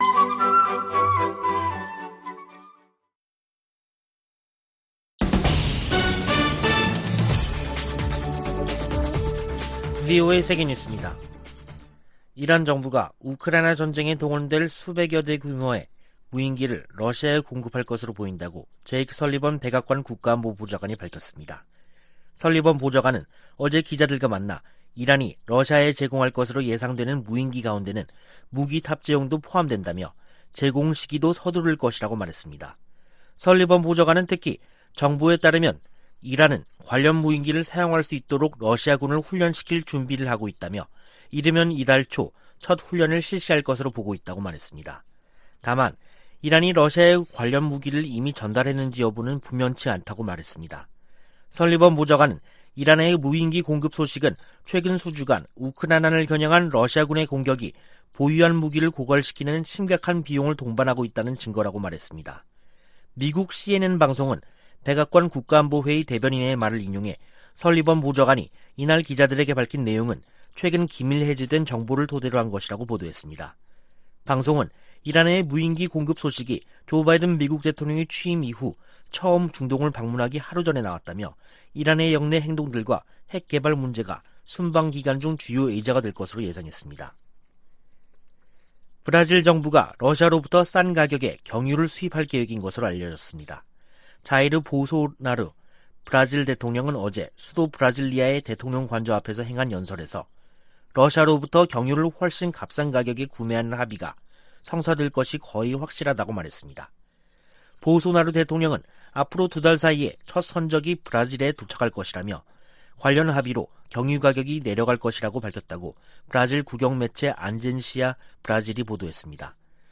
VOA 한국어 간판 뉴스 프로그램 '뉴스 투데이', 2022년 7월 12일 2부 방송입니다. 올 후반기 미-한 연합지휘소훈련이 다음달 22일부터 9월1일까지 실시될 전망입니다. 북한 군이 4주 만에 방사포 발사를 재개한 것은 한국을 실제로 타격하겠다는 의지와 능력을 과시한 것이라고 전문가들은 진단했습니다. 마크 에스퍼 전 미 국방장관은 주한미군에 5세대 F-35 스텔스기를 배치해야 한다고 주장했습니다.